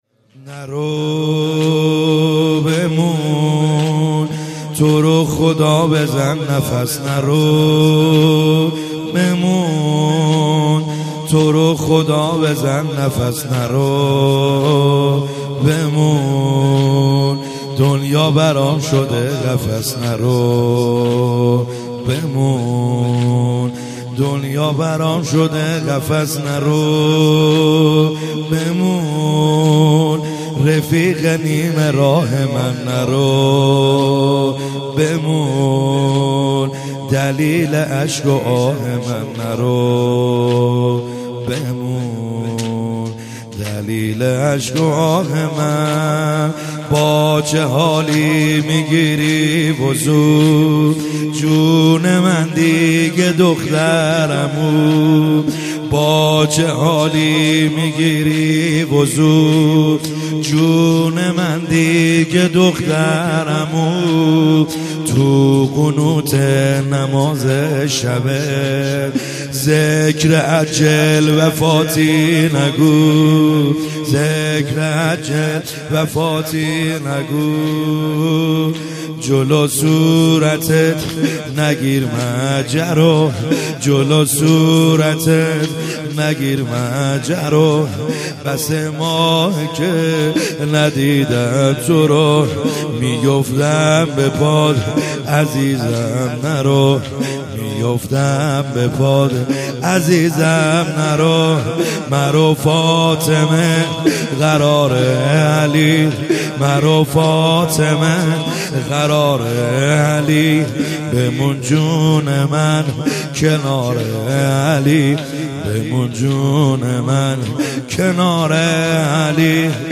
خیمه گاه - بیرق معظم محبین حضرت صاحب الزمان(عج) - زمینه | نرو بمون توروخدا